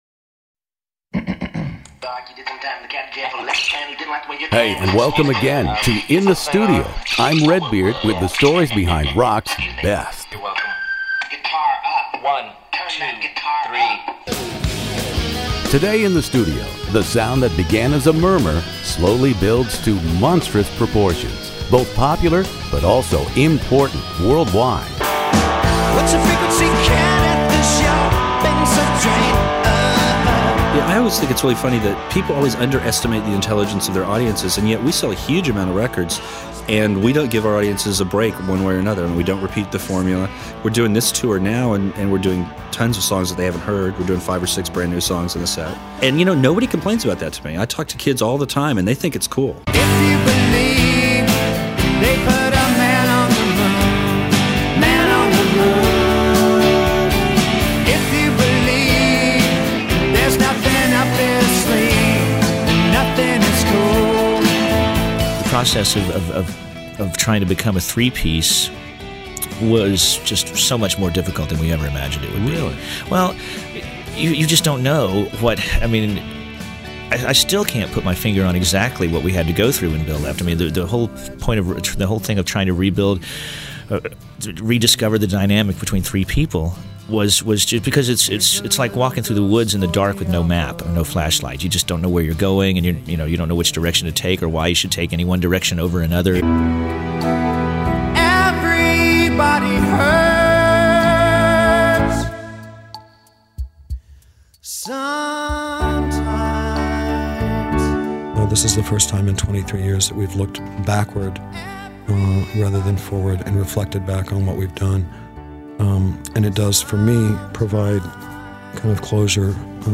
REM “Monster” interview with Michael Stipe, Peter Buck, Mike Mills In the Studio